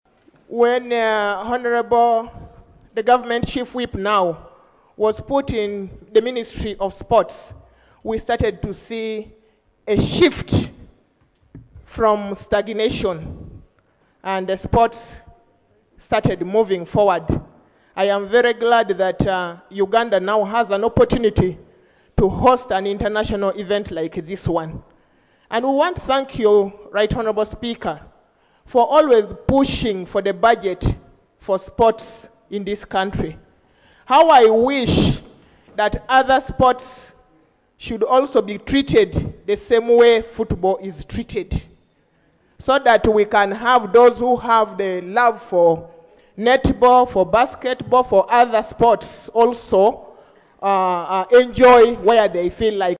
Legislators raised the concerns during debate on a statement by the Minister of State for Sports, Hon. Peter Ogwang on Wednesday, 30 July 2025.
Hon. Susan Amero (Amuria District Woman Representative) used the occasion to call for balanced funding across all sports.